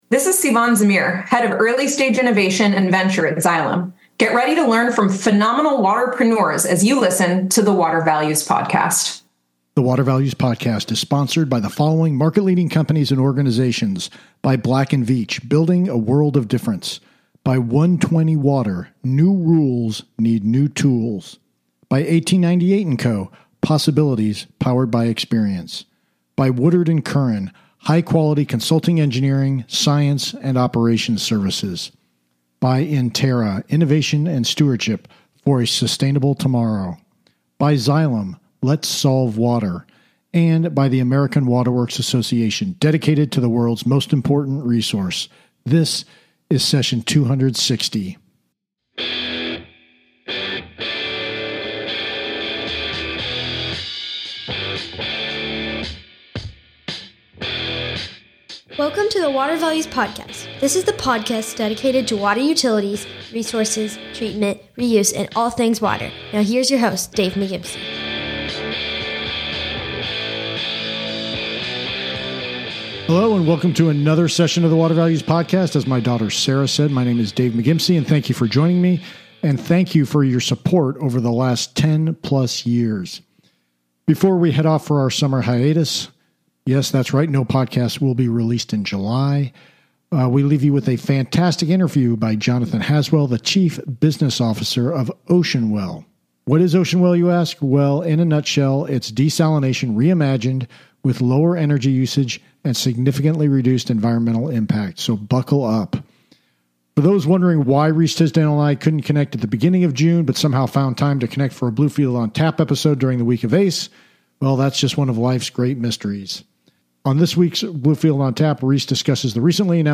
including the significant environmental and energy benefits in this can’t miss interview.